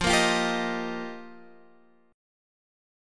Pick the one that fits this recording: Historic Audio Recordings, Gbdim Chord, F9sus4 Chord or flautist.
F9sus4 Chord